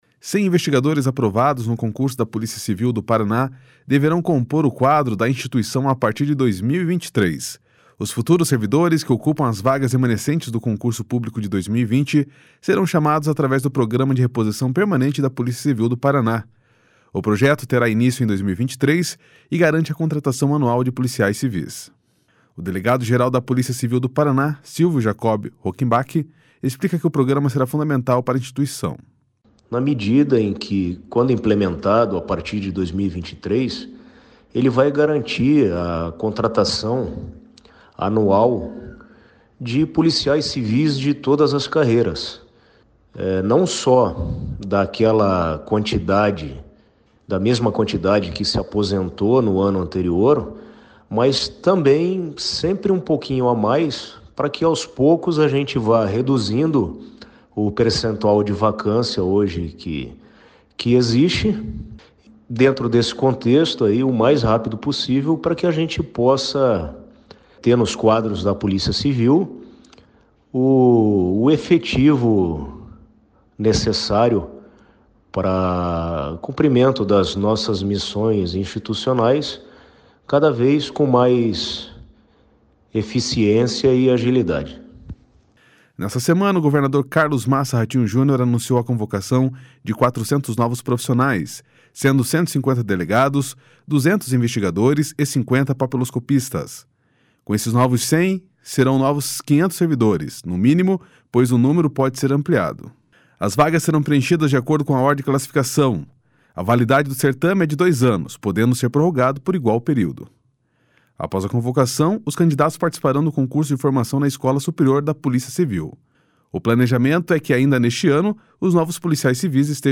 O delegado-geral da Polícia Civil do Paraná, Silvio Jacob Rockembach, explica que o programa será fundamental para a instituição.